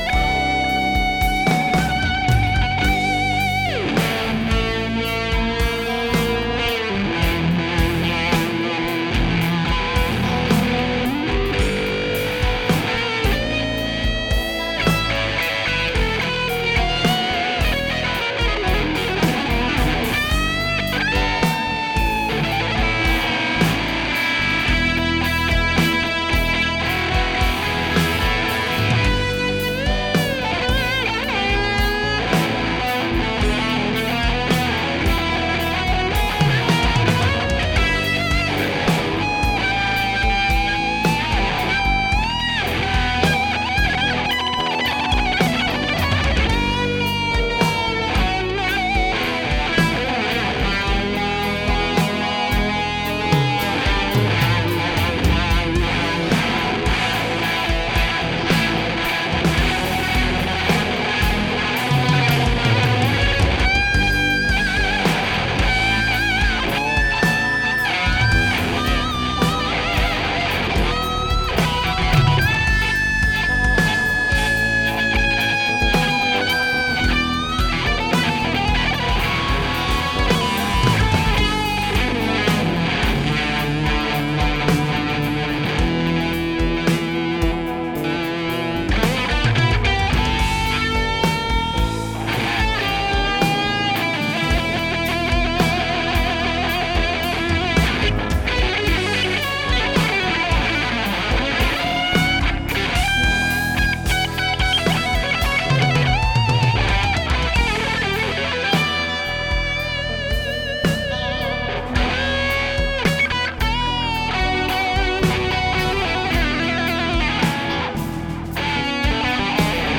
(le 1er c'est toi, ensuite moi faut bien prendre en compte que j'ai pas les memes ir et la meme gratte, rien que ça c'est chaud.)